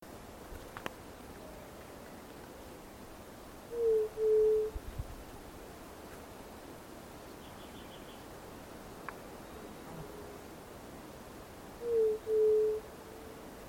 Yerutí Común (Leptotila verreauxi)
Nombre en inglés: White-tipped Dove
Fase de la vida: Adulto
Localidad o área protegida: Parque Nacional Mburucuyá
Condición: Silvestre
Certeza: Observada, Vocalización Grabada